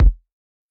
AssMilk_Kick.wav